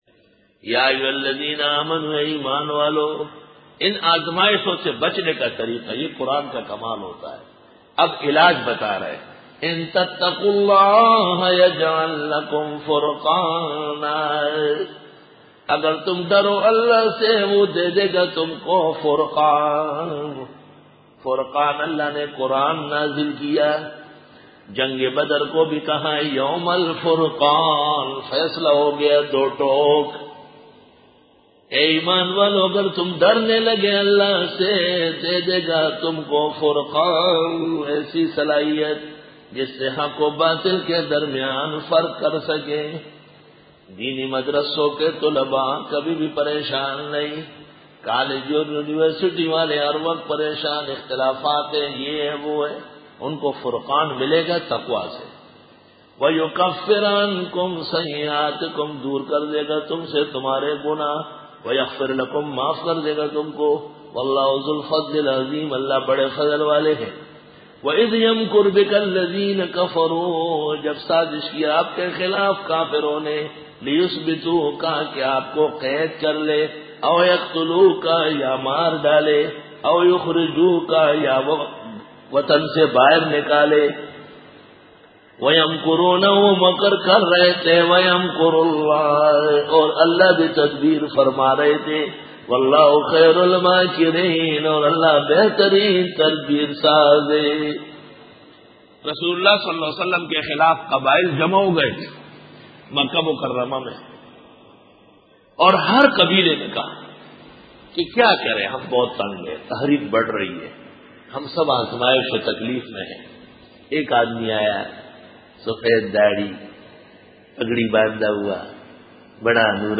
سورۃ الانفال رکوع-04 Bayan